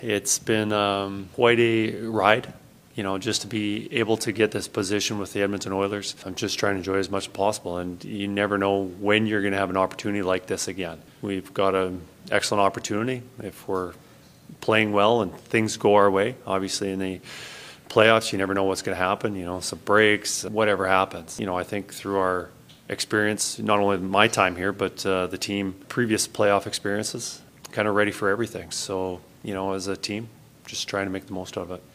Knoblauch reflected to the media about his opportunity.